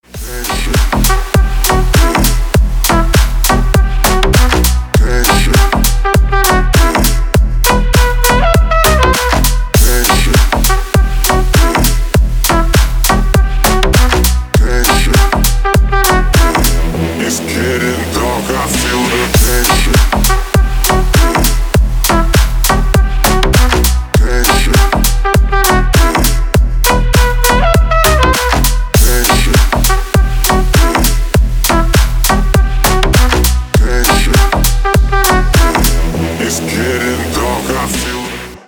• Качество: 320, Stereo
мужской голос
громкие
deep house
Electronic
EDM
духовые
Стиль: deep house